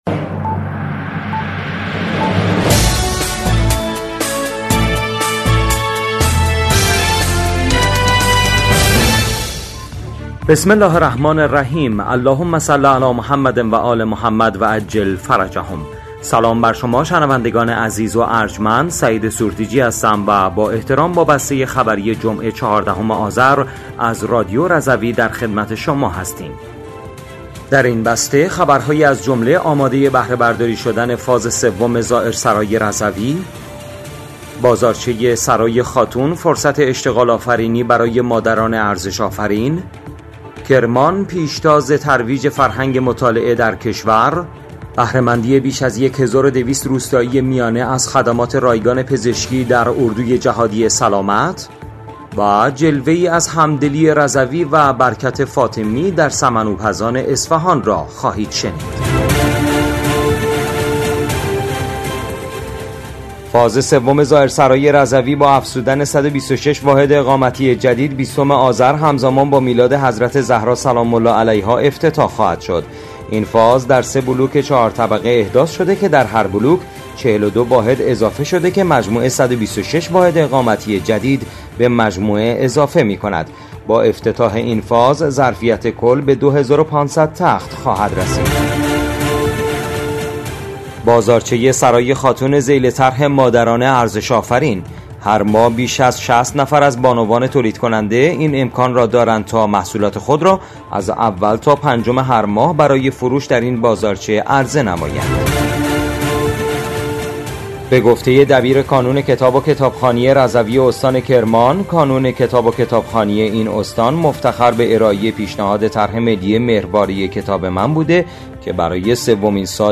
بسته خبری هفتگی ۱۴ آذر ۱۴۰۴ رادیو رضوی؛